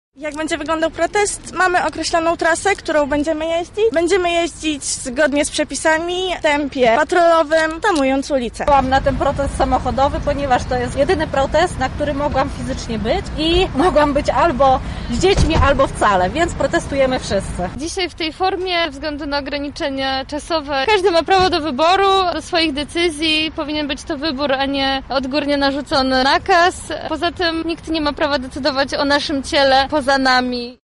O tym dlaczego protestują w takiej formie i jak będzie wyglądał strak mówiły uczestniczki wydarzenia.